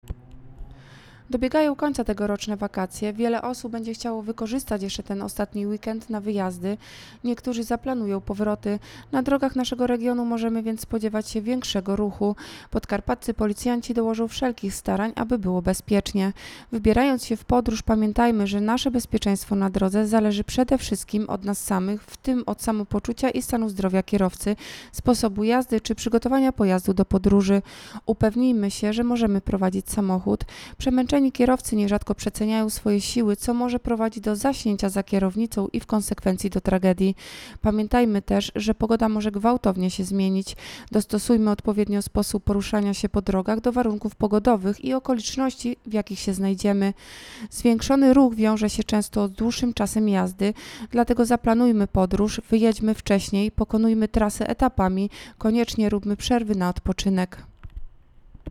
Nagranie audio Ostatni weekend wakacji. Wspólnie zadbajmy o bezpieczeństwo - mówi nadkom.